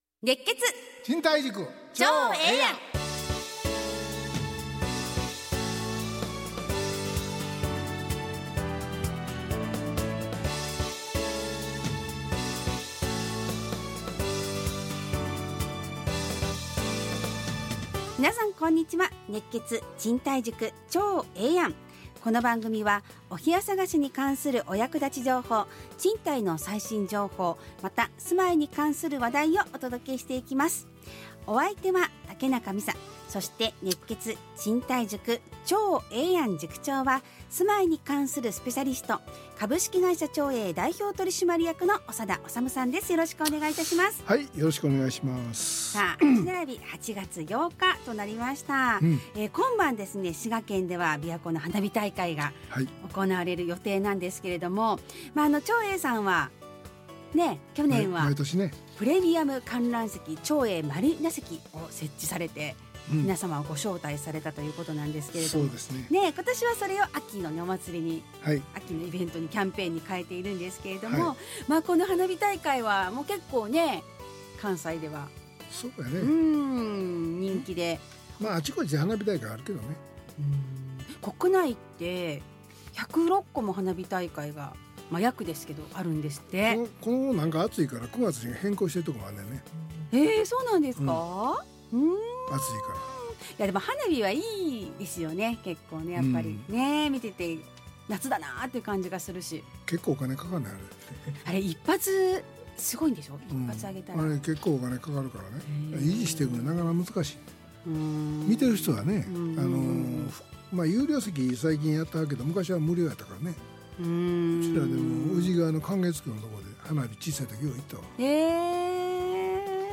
ラジオ放送 2025-08-08 熱血！賃貸塾ちょうええやん【2025.8.8放送】 オープニング：びわ湖大花火大会、ベルヴィクラブのご招待キャンペーンは秋に開催 ちょうえぇ通信：「お盆」 賃貸のツボ：お部屋探しの時に壁の厚さがわかるようなデータがあると嬉しいです。